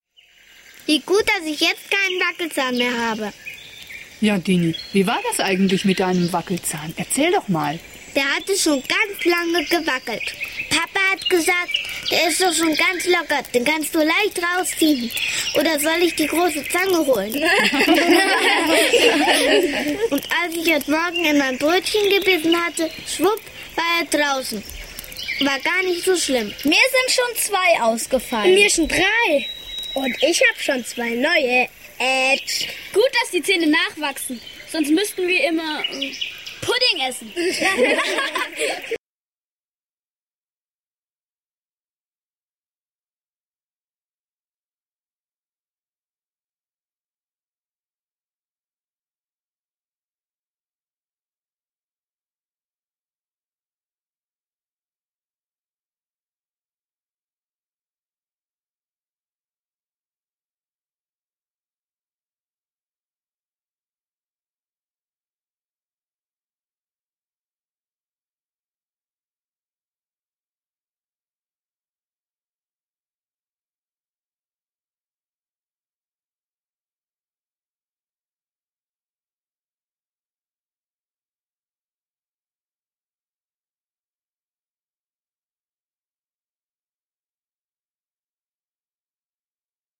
Hörszene 6